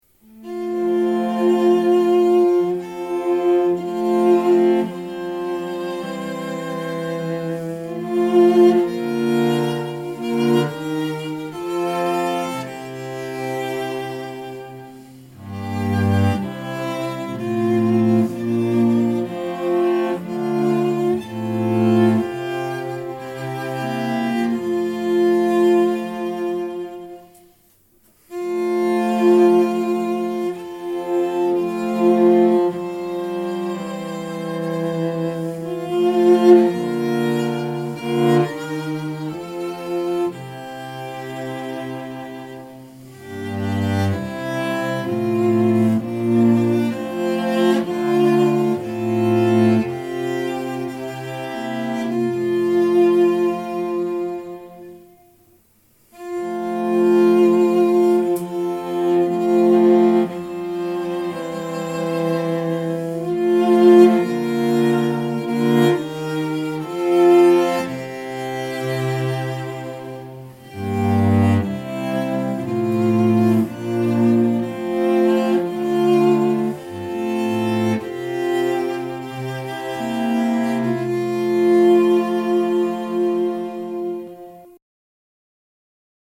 ‘Drop, Drop Slow Tears’ by Orlando Gibbons arranged and performed by me